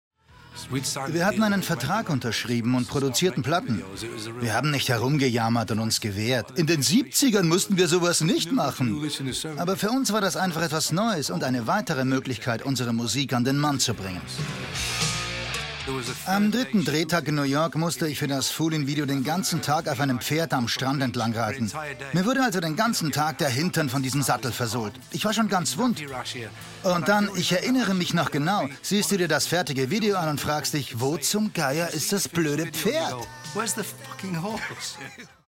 REFERENZEN ll ausgebildeteter Schauspieler mit 40 Jahren Berufserfahrung: TV / Film / Serie / Theater / Werbung / Synchron Kraftvolle, sonore, lebendige, warme Stimme für: Werbung-druckvoll- Dokumentationen / off voice / Telefonsysteme / Guides ( Museum, Stadtführungen) Hörbuch, Hörspiele / Radiogeschichten / Lyrik / Lesungen Einkaufsradio / Dokusoap / Kinowerbung / Messen Trickstimmen TV-Sende: Arte, SWR, ORF Werbung: Einkaufsradio ( Hauptsprecher), Spots, Off Voice, Synchron, Dokumentation, PC- Games: Lesungen, Moderationen usw. viel, viel für arte Trickstimme ( Kinderpingui ) und vieles mehr Hörbücher, Kinderliteratur.
Sprechprobe: Sonstiges (Muttersprache):